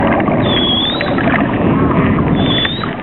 دانلود صدای دلفین 8 از ساعد نیوز با لینک مستقیم و کیفیت بالا
جلوه های صوتی